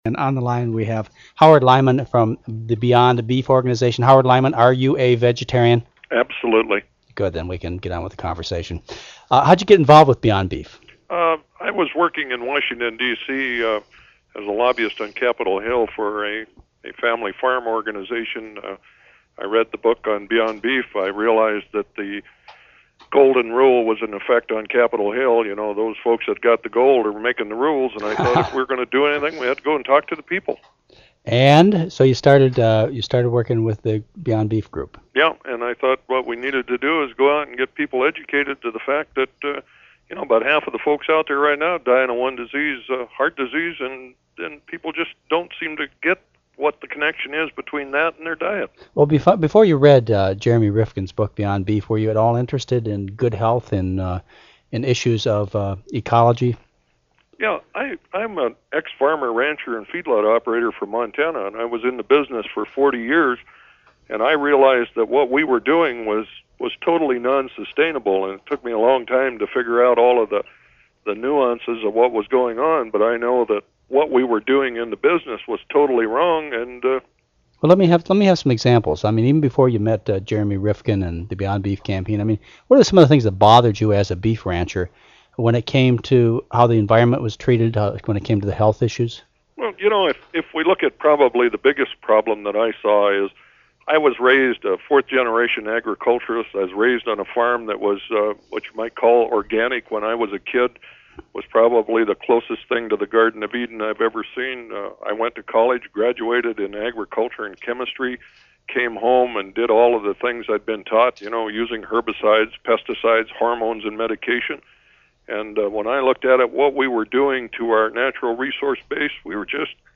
The Mad Cowboy (taped interview)